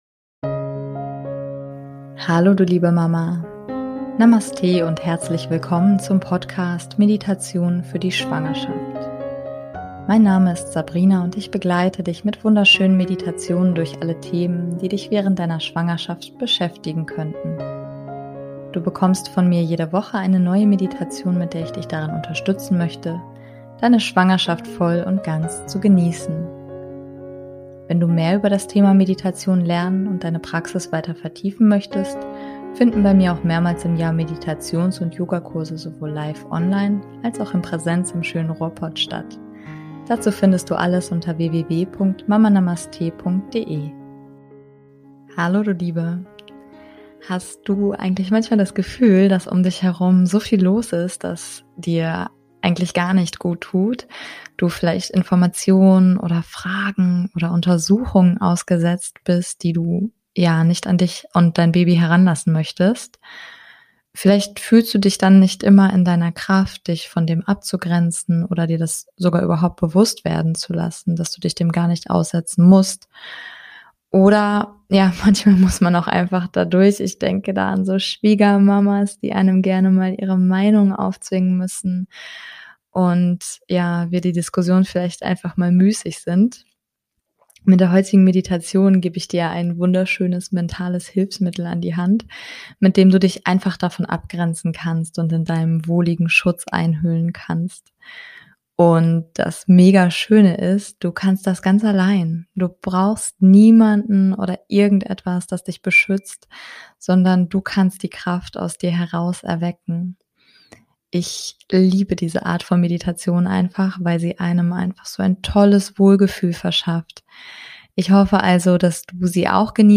#066 - Meditation Ein Mantel aus Schutz für dein Baby und dich ~ Meditationen für die Schwangerschaft und Geburt - mama.namaste Podcast